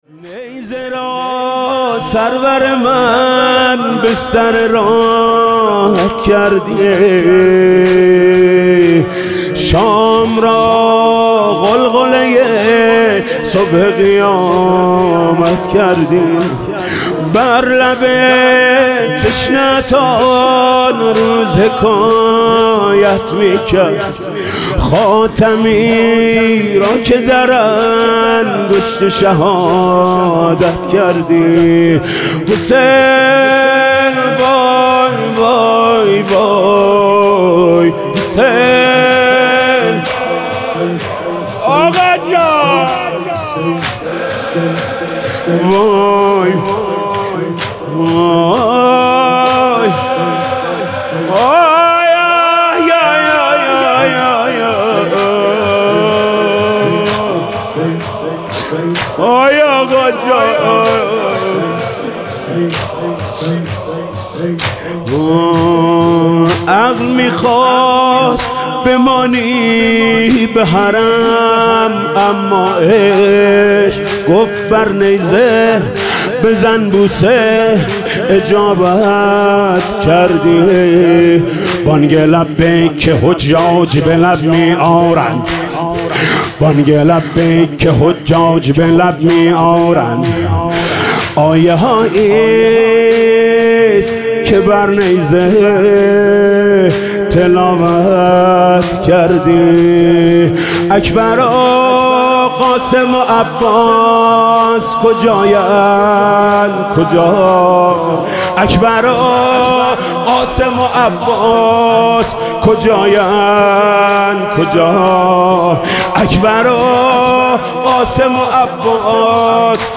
شور: نیزه را سرور من بستر راحت کردی